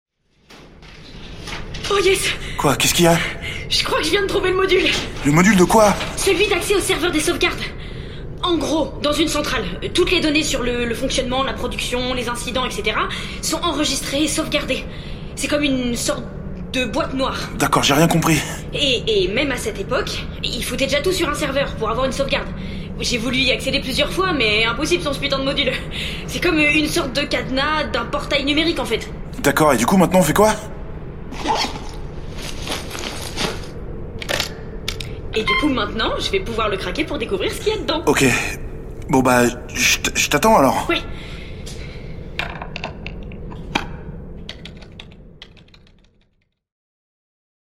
Fiction sonore "Nuclear Ghost" - Extrait 3